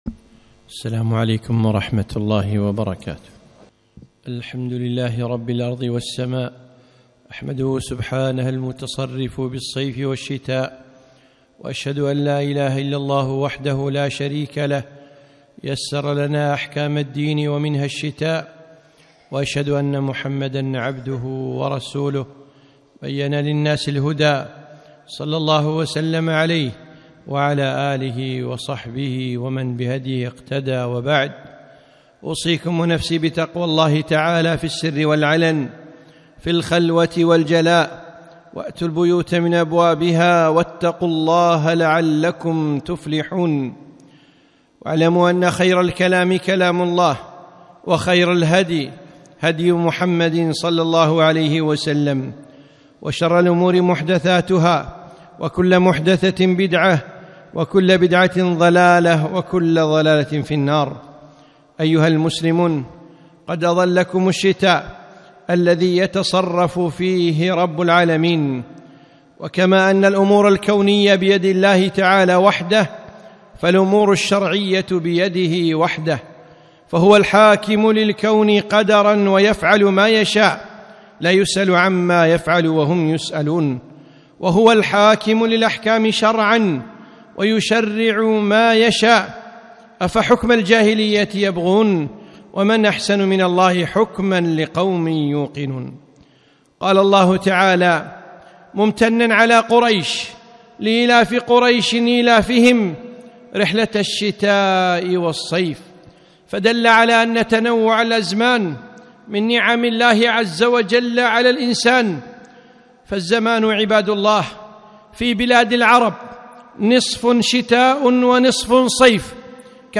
خطبة - إهداء ببيان حكم الشتاء